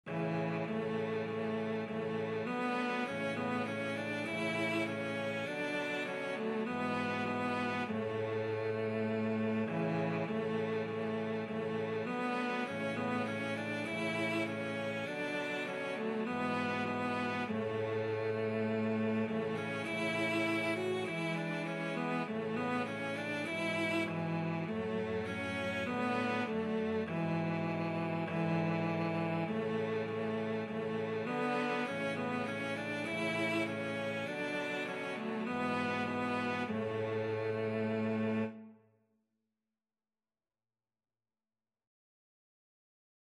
Cello 1Cello 2Cello 3Cello 4
4/4 (View more 4/4 Music)